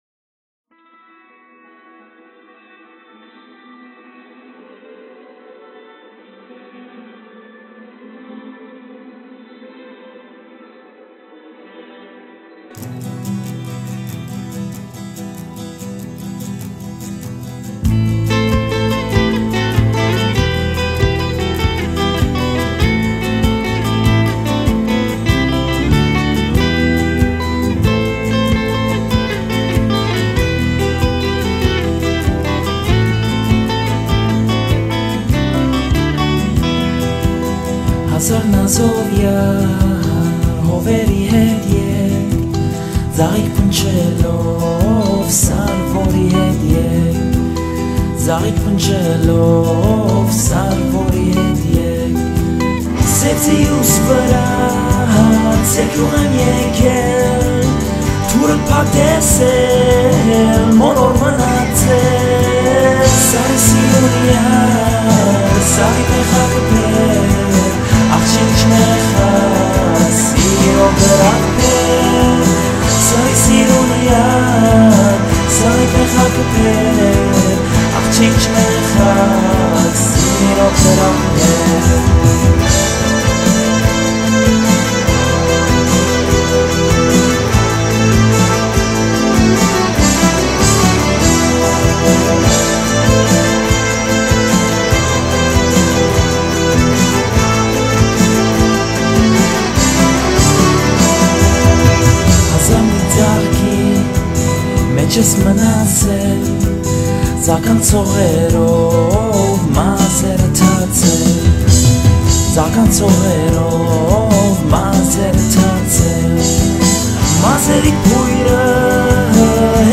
Listen to another version If you’re looking for a version that harmonizes the rock and folk genres more distinctly